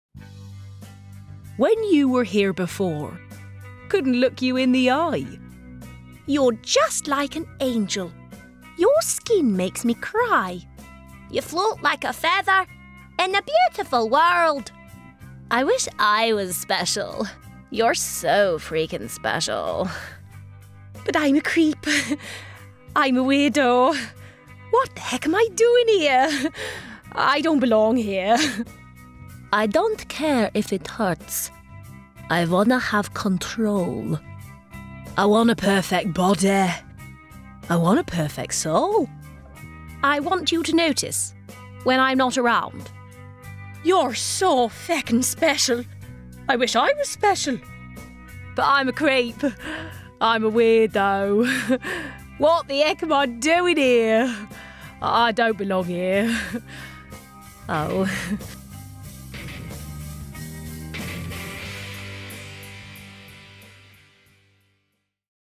• Native Accent: Hereford, West Country
• Home Studio